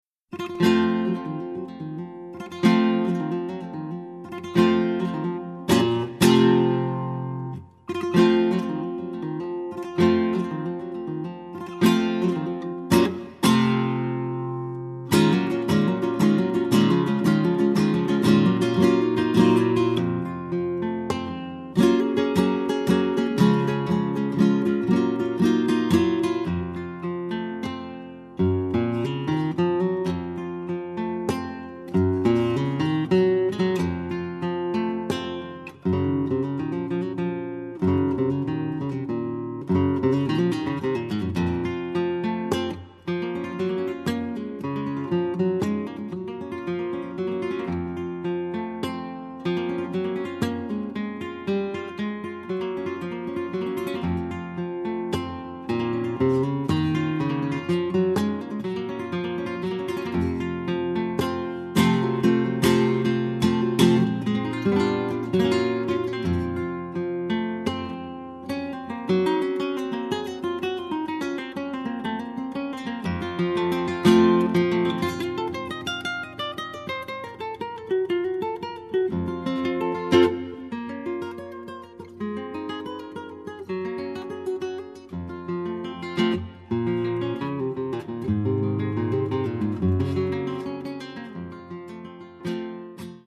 Albany Flamenco Guitarist 1
This internationally acclaimed Albany Flamenco Guitarist, from New York, is one of the few touring female flamenco guitarists in the world. Her program offers a range of Spanish inspired music from Traditional flamenco puro to nuevo flamenco.